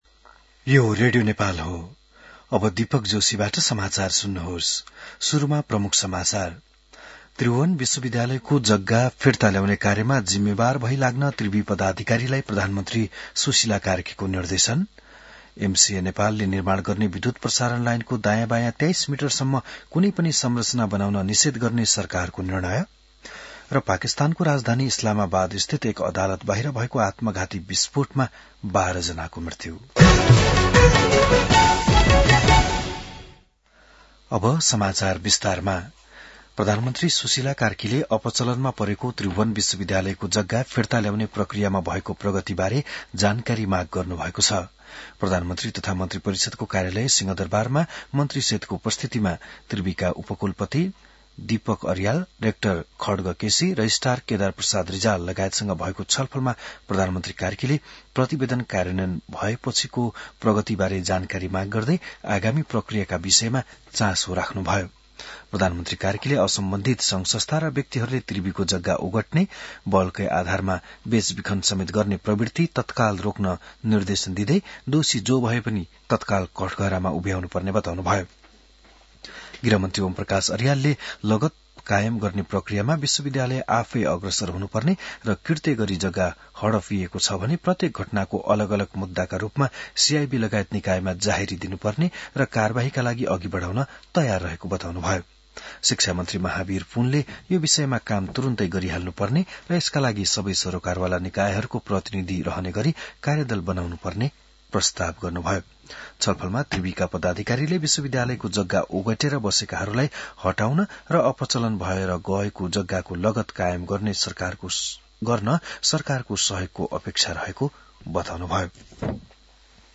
बिहान ९ बजेको नेपाली समाचार : २६ कार्तिक , २०८२